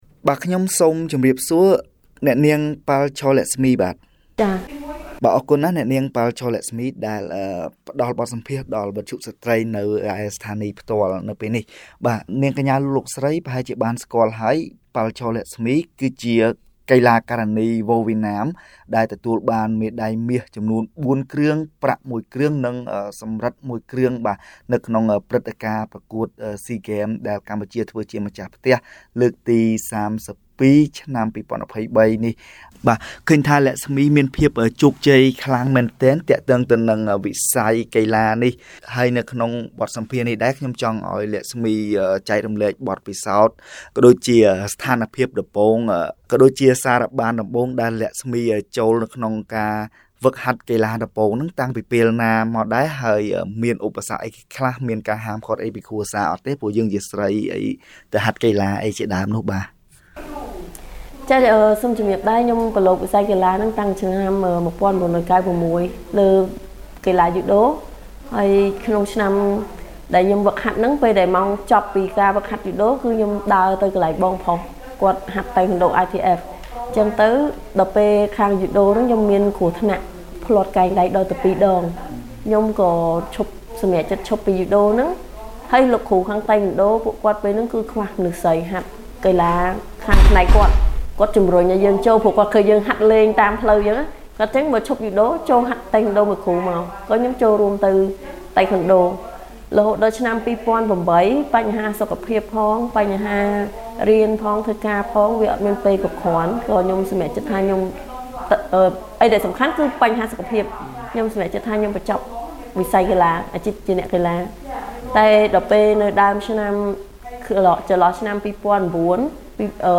(បទសម្ភាស)